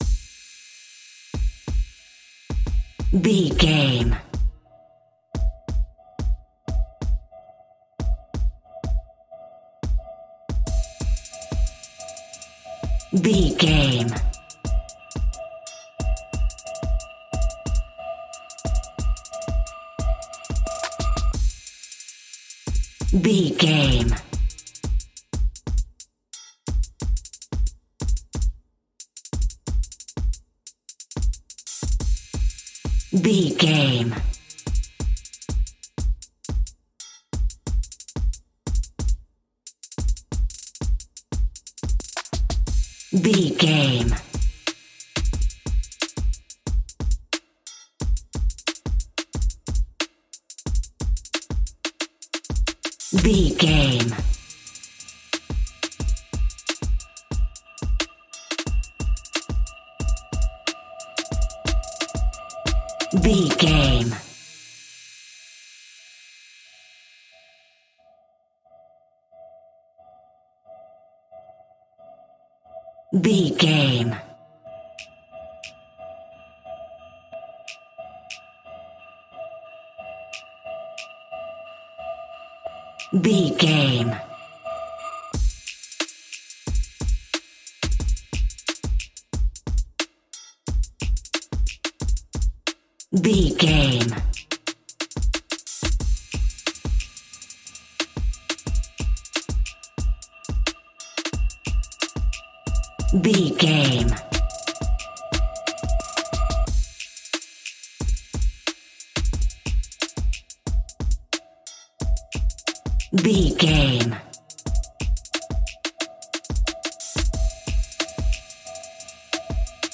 Aeolian/Minor
Slow
eerie
groovy
dark
drum machine